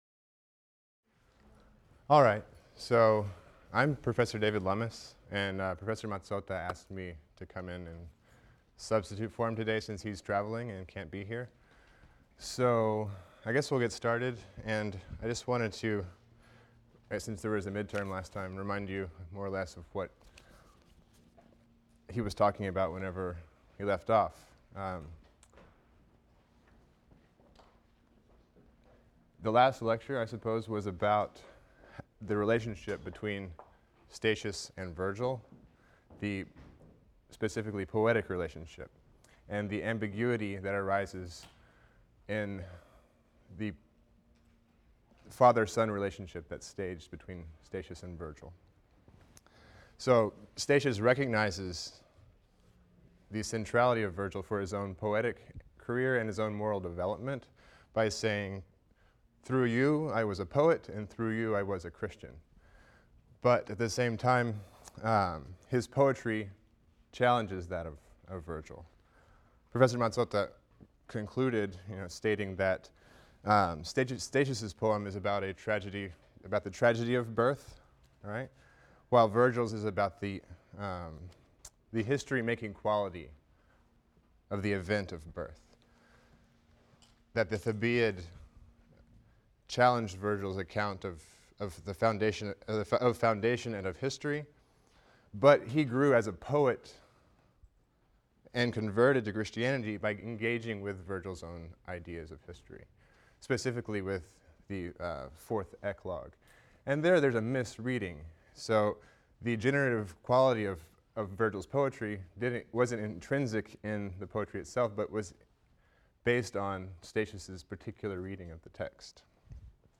ITAL 310 - Lecture 14 - Purgatory XXIV, XXV, XXVI | Open Yale Courses